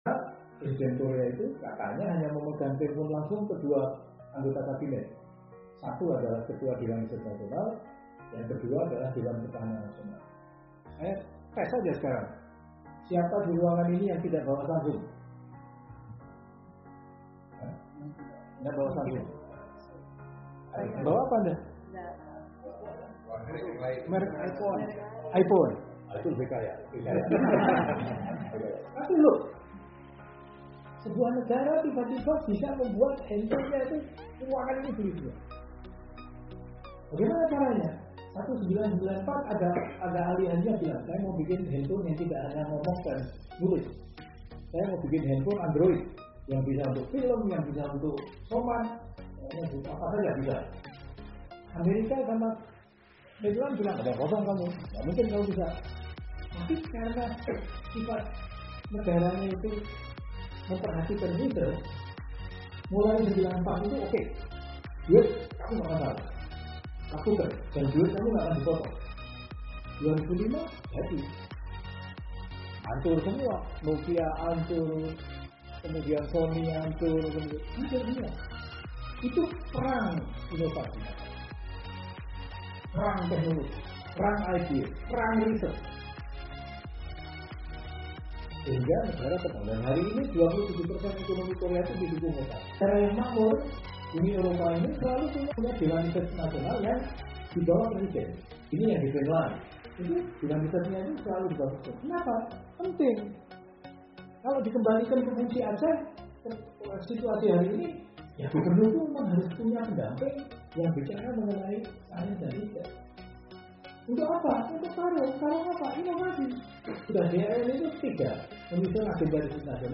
Sebagai salah satu Badan Usaha dalam Bidang Penelitian yaitu Lemristek LP2STM mengudarakan sebuah program Siaran Radio “Iptek Voice” audio/video yang bertempat di Studio Mini LP2STM Syiah Kuala, Kota Banda Aceh, Indonesia.
Announcer      : DR. Ir. Bambang Setiadi, IPU (Ketua DRN RI)
Format             : Talkshow, music
Gaya penyiar : Dewasa, dan smart